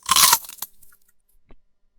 Crunch! …That loud, crisp snap as my teeth sink into a fresh, juicy apple.
The skin breaks, the flesh pops, and the sound echoes in the silence.
Each bite is clean, sharp, and satisfying.”
오히려 과자를 먹을 때 생기는 소리도 있는 것 같고 가장 사과를 베어 물때 어떤 효과음이 잘 어울릴지 고민하고 선택하시면 되겠죠?